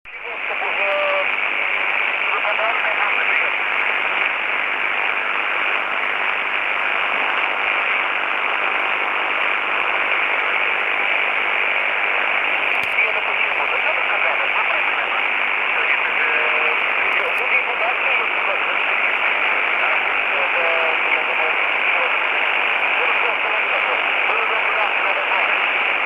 У меня- дело техники, что-бы не передавать тоном 1.5 кГц, принимаю на Р-309(м) с кварцевым фильтром, передаю на Р-326М, который сейчас элементарный передатчик.